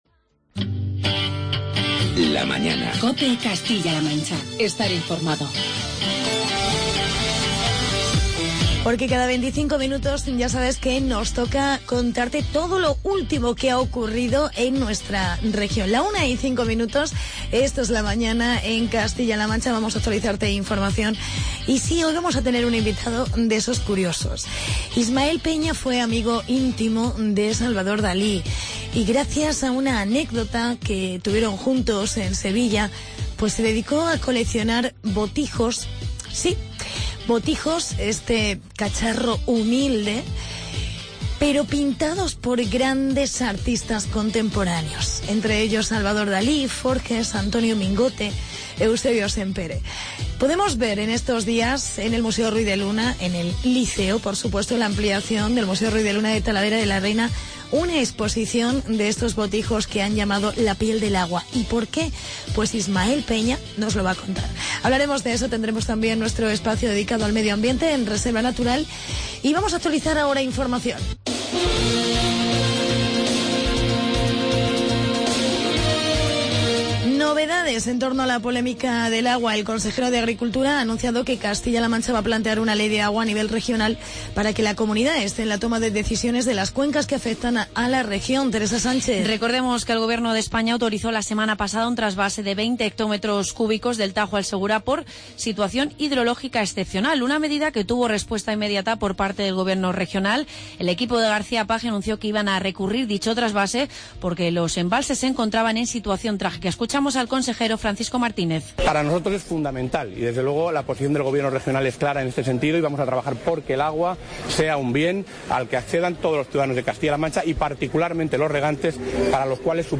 Hablamos con el coleccionista